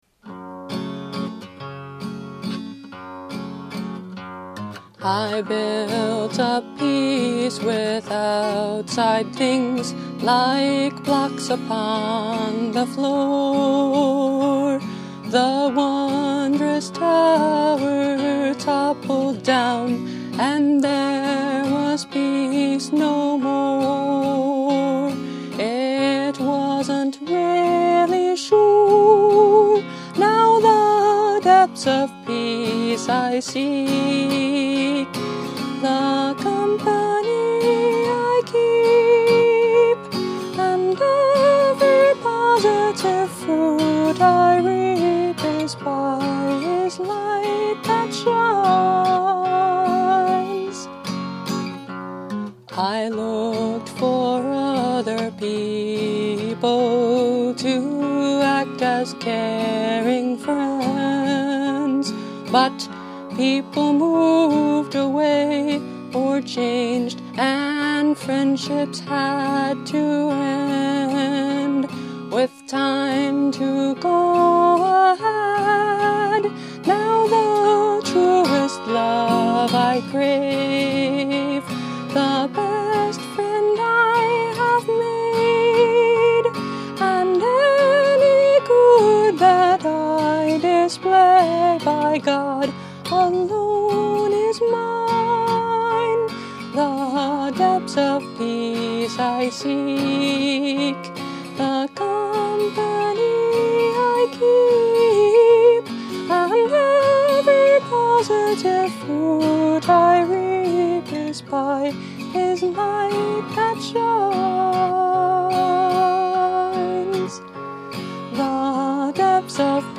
Instrument: Tempo – Seagull Excursion Folk Acoustic Guitar
(Capo 2)